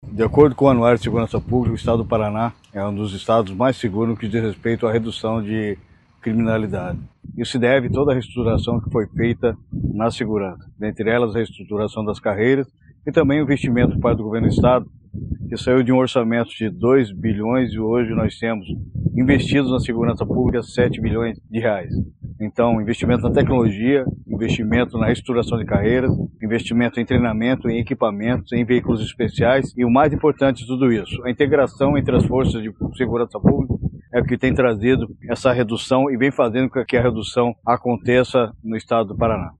Sonora do secretário de Segurança Pública, Hudson Leôncio Teixeira, sobre o Paraná estar entre os estados mais seguros do Brasil